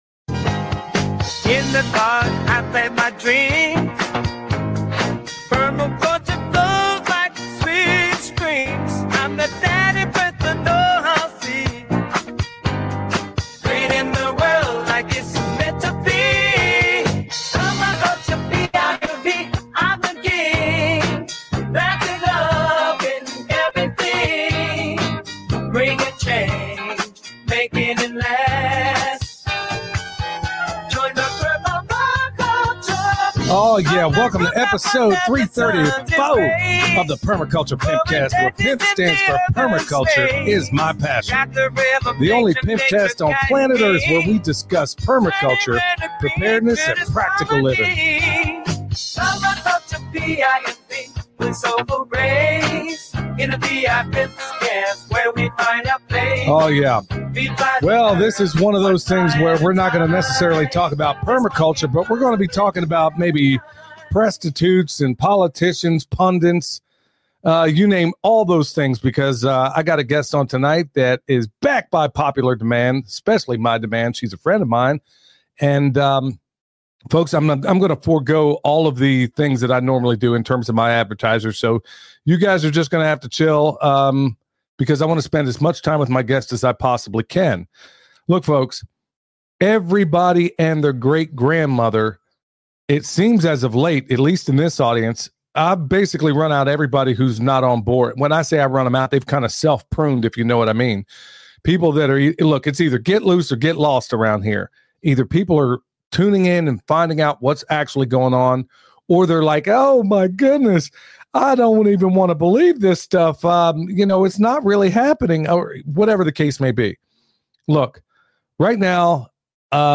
Ep. 334 - Florida vs. Chemtrails: Did They Really Stop It? Interview